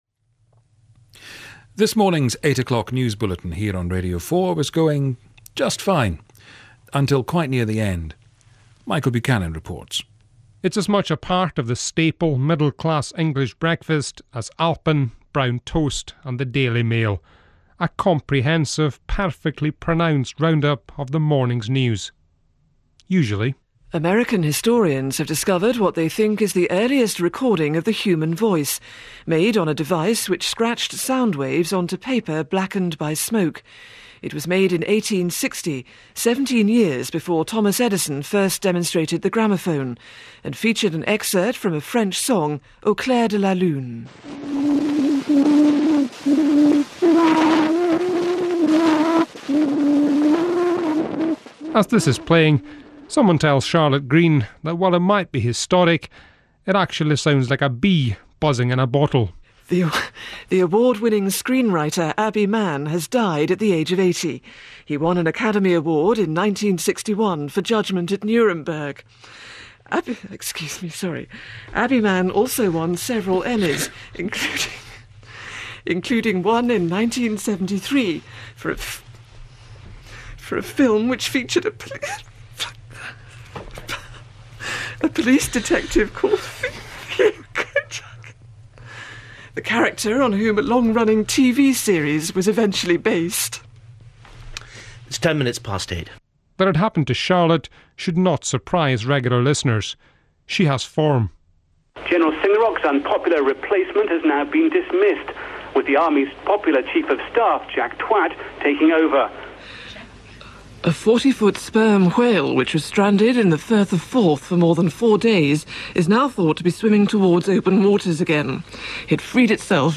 The coverage of this story on British radio gave rise to one of the funniest things I've heard in a long time -